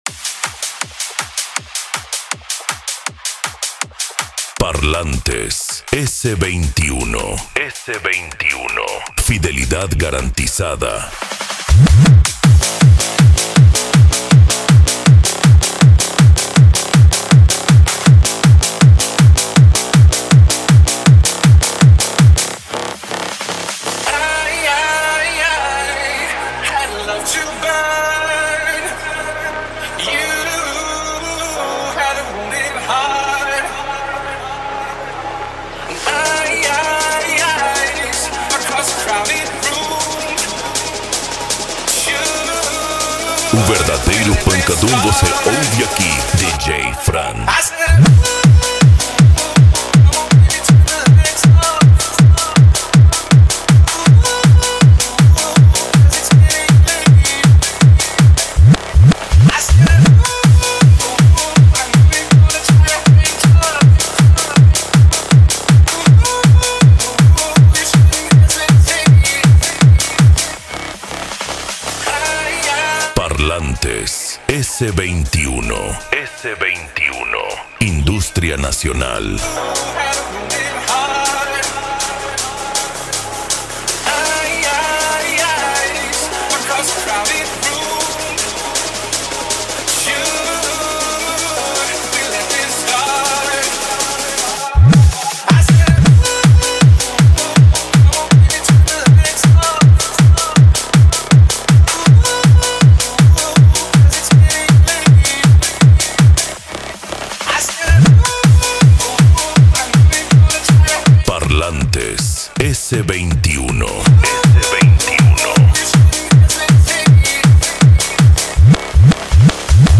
Eletronica
PANCADÃO
Psy Trance
Remix